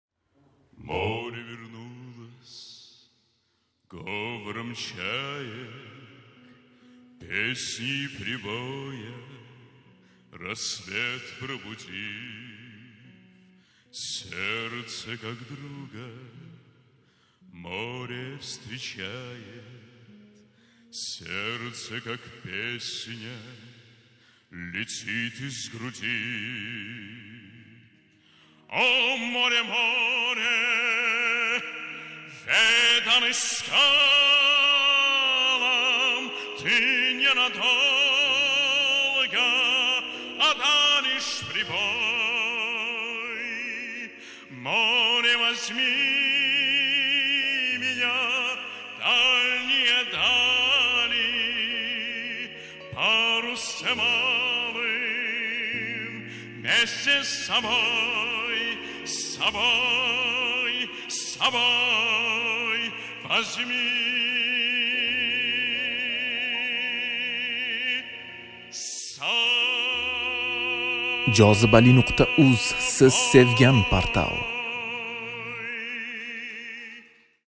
CANLI İFA